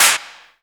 SUBCLAP.wav